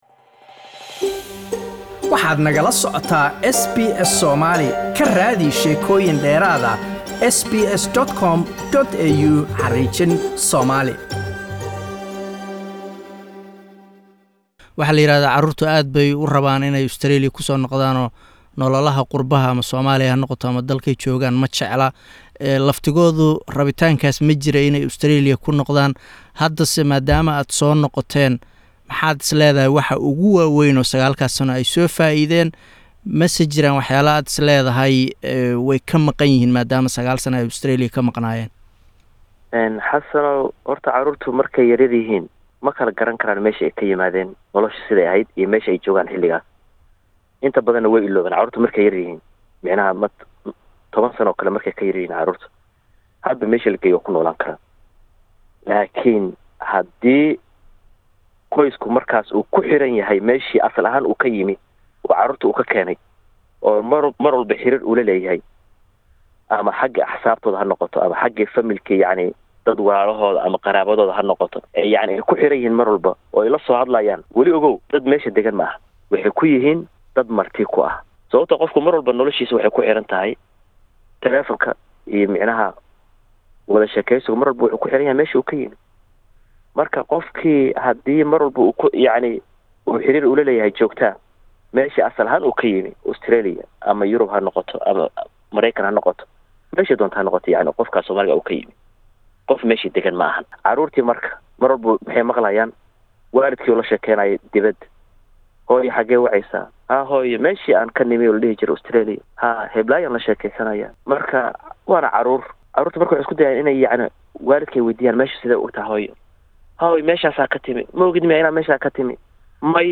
Caruurta laga kaxeeyo qurbaha oo dib loogu celiyo Africa ama dal Muslim ahaa inta badan dib ayaa loo soo celiyo waxyar kadib, maxaa sababay? Waxaa nooga waramay aabe caruutiisa sanado kusoo hayay Kenya haddana dib ugu soo celiyay Australia.